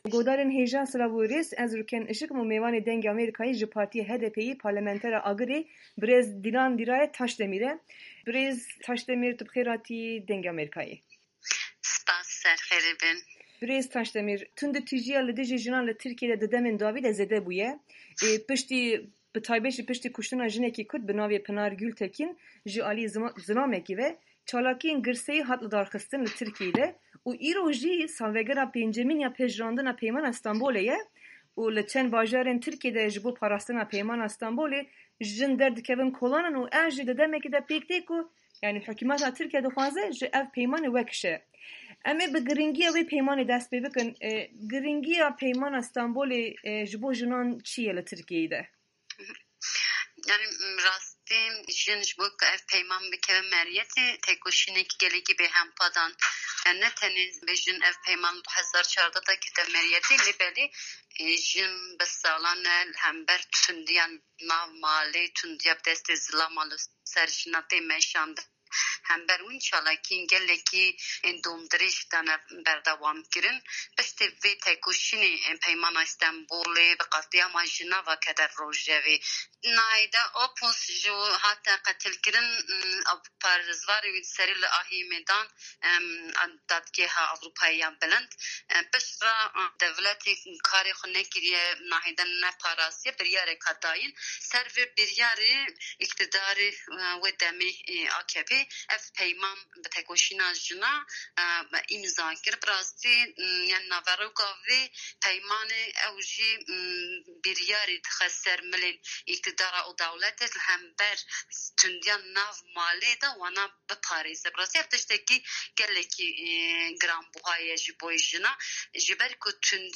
Tirkîye - Hevpeyvîn
Dilan Taşdemir, parlementera HDPê- Agirî